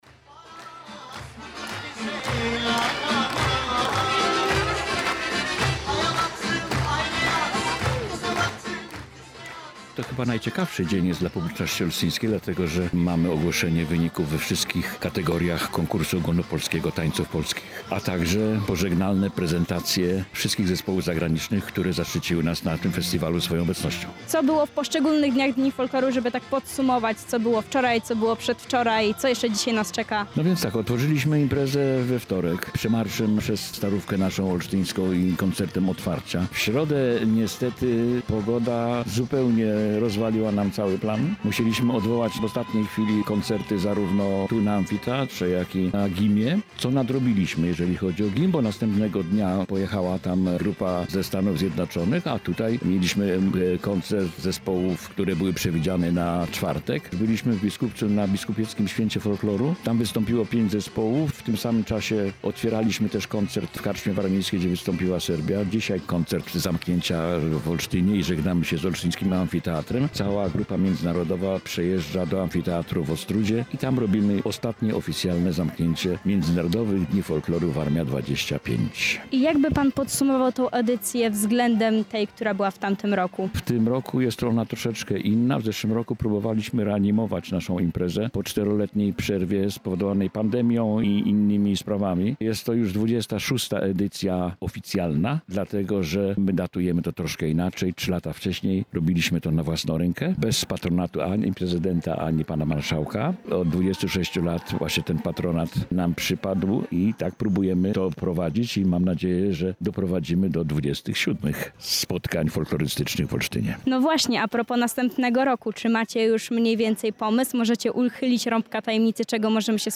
Więcej o wydarzeniu dowiecie się z relacji naszych reporterów.